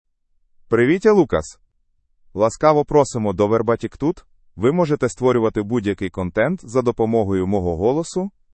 MaleUkrainian (Ukraine)
Lucas — Male Ukrainian AI voice
Lucas is a male AI voice for Ukrainian (Ukraine).
Voice sample
Lucas delivers clear pronunciation with authentic Ukraine Ukrainian intonation, making your content sound professionally produced.